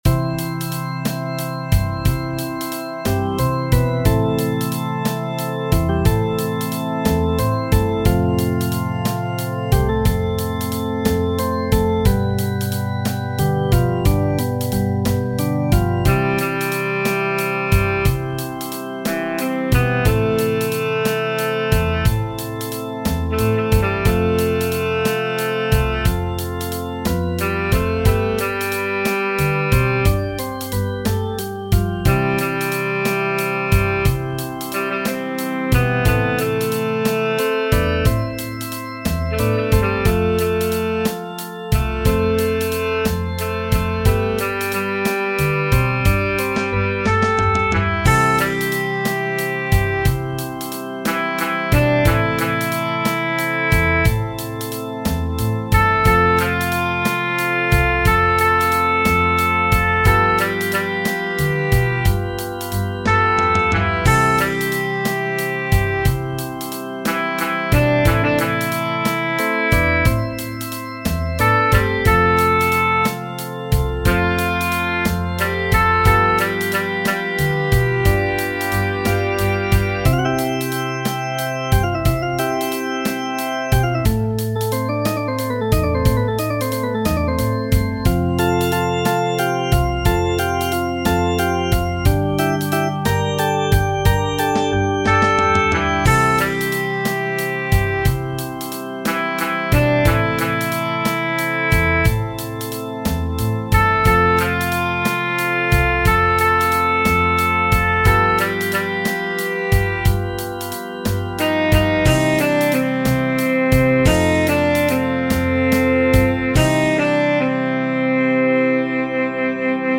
musica beat